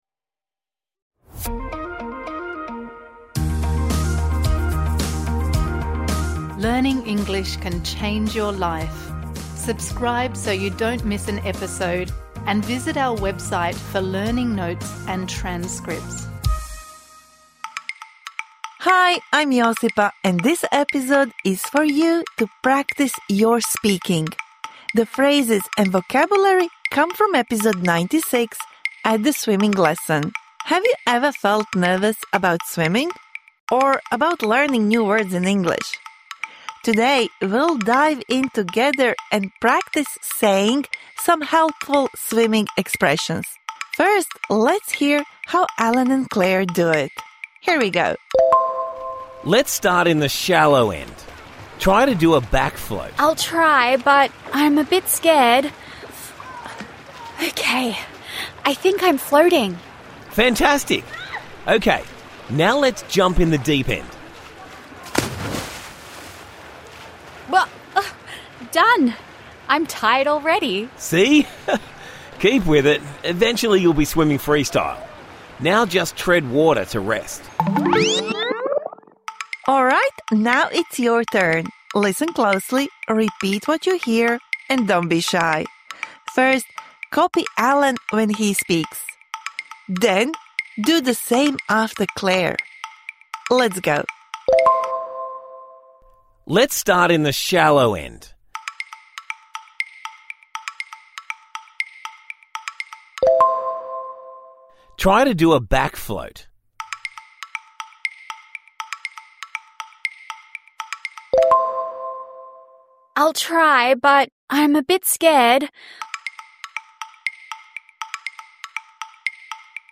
This bonus episode provides interactive speaking practice for the words and phrases you learnt in #96 At a swimming lesson (Med).